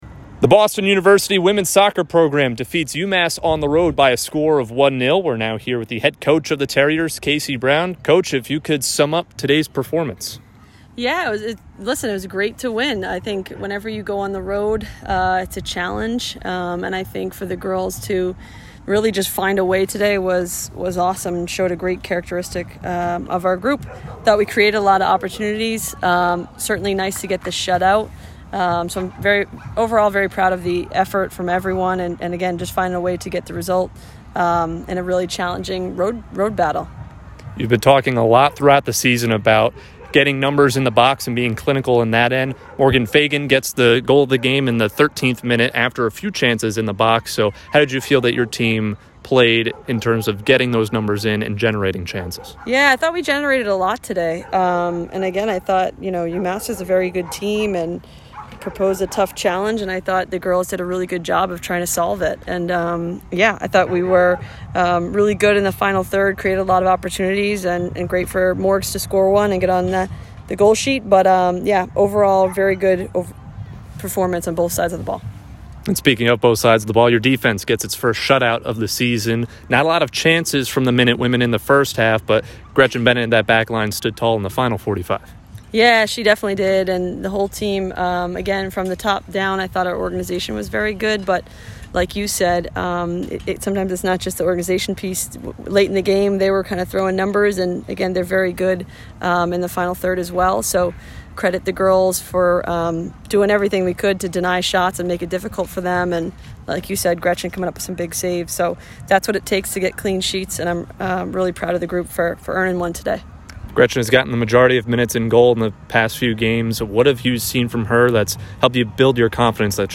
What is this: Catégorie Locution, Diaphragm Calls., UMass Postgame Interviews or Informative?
UMass Postgame Interviews